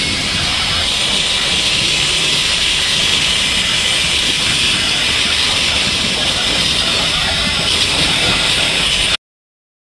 Пламя и писк:
fire5.wav